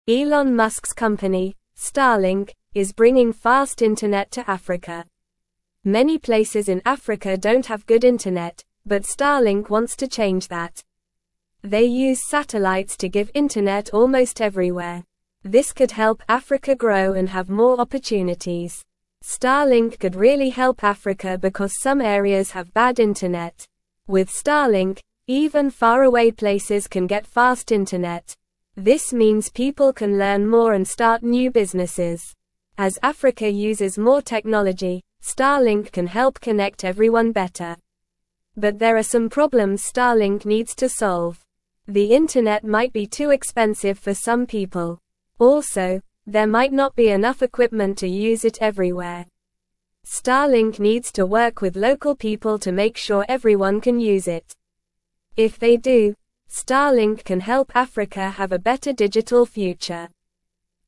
Normal
English-Newsroom-Lower-Intermediate-NORMAL-Reading-Starlink-brings-fast-internet-to-Africa-to-help-people.mp3